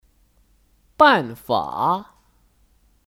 办法 (Bànfǎ 办法)